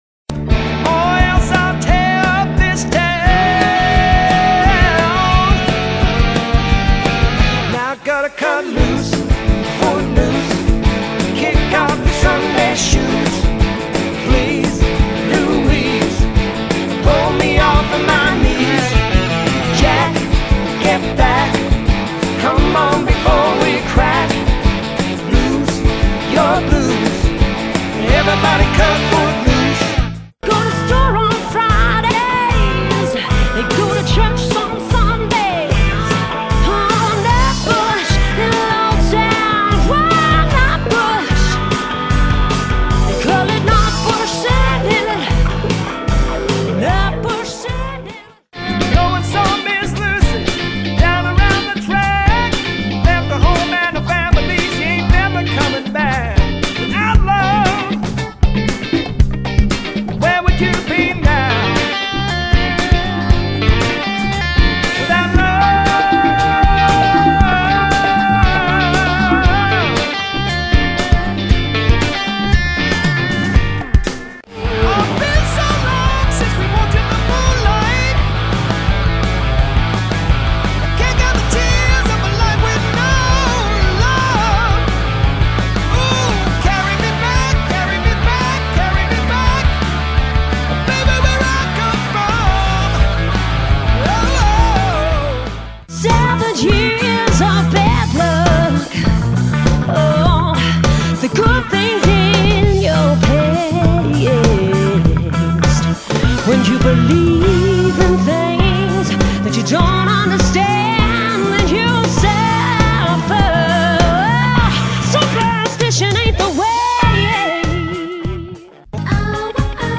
“covers dance band”
This six piece power house can do it all.